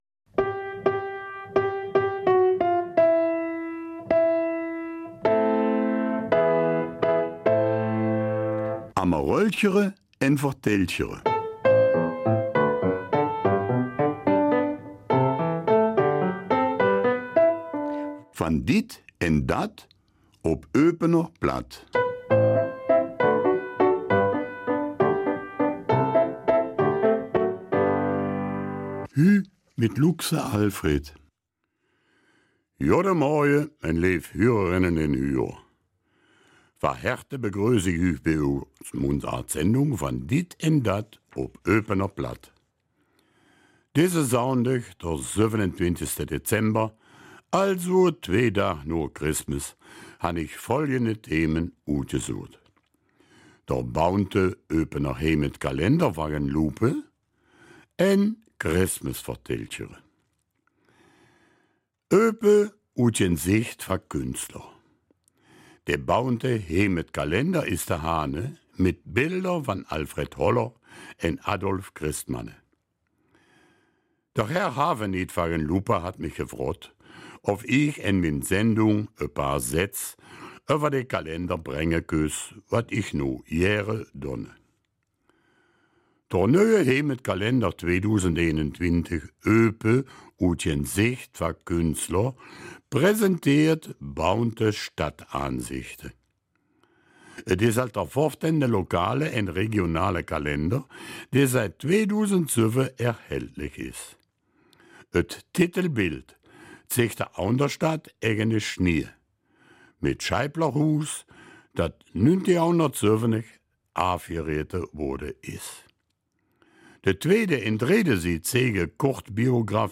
Eupener Mundart: Heimatkalender 2021